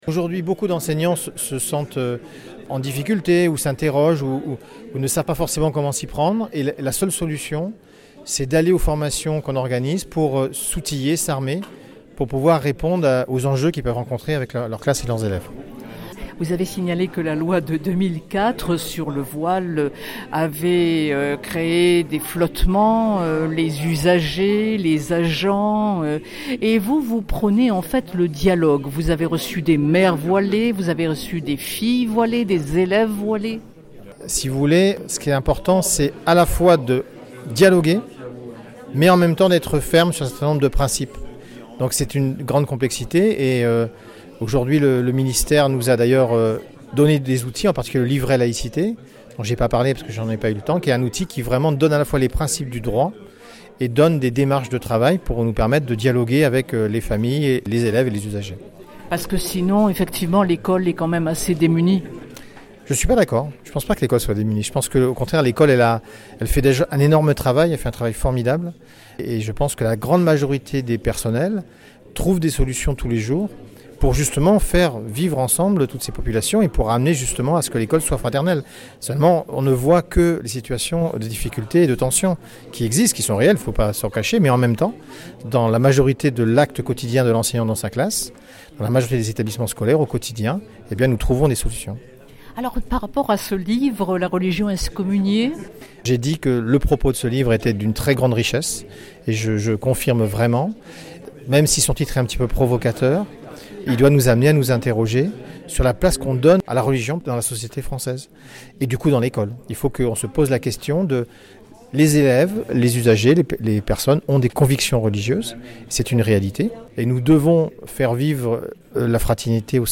Marseille: riche débat à l’Institut Catholique de la Méditerranée sur ce que devient la laïcité